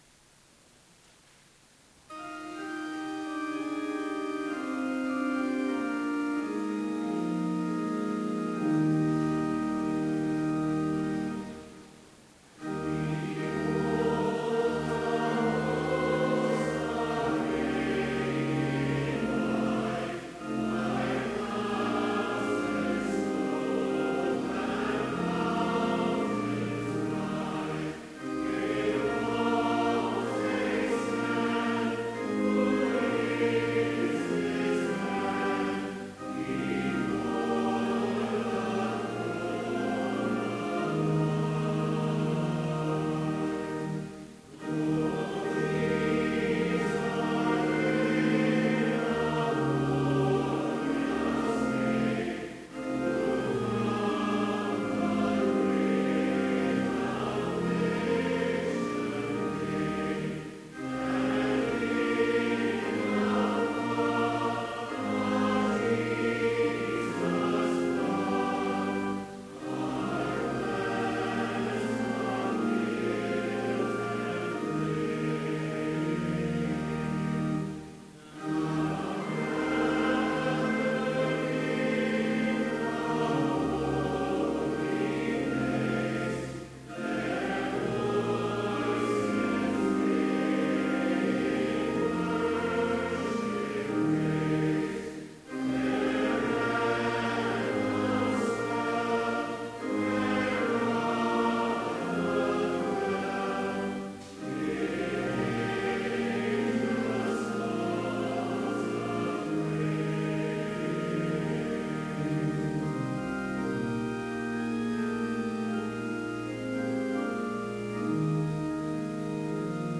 Complete service audio for Chapel - November 3, 2015
Watch Listen Complete Service Audio file: Complete Service Sermon Only Audio file: Sermon Only Order of Service Prelude Hymn 553, vv. 1 & 2, Behold a Host, Arrayed in White Reading: Hebrews 11:37-40 GWN Devotion Prayer Hymn 553, v. 3, Then hail! Ye mighty… Blessing Postlude Scripture Hebrews 11:37-40 GWN They were stoned, tempted, sawed in half, murdered with a sword.